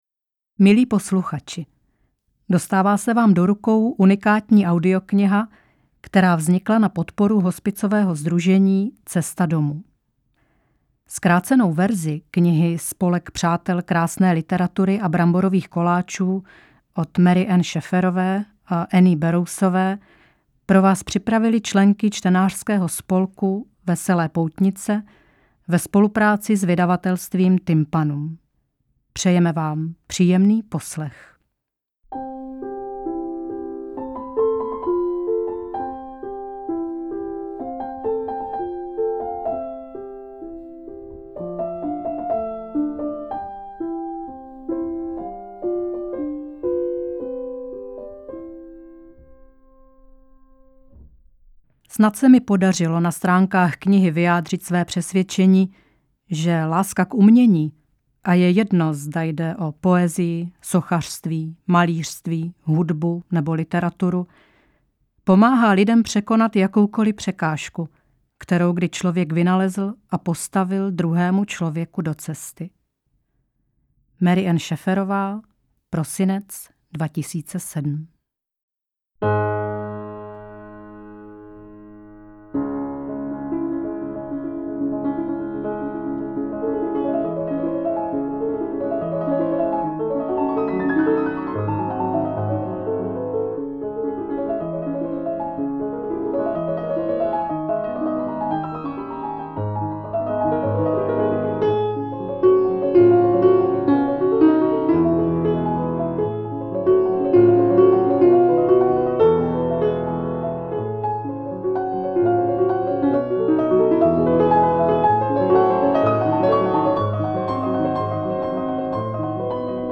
AudioKniha ke stažení, 66 x mp3, délka 4 hod. 39 min., velikost 557,6 MB, česky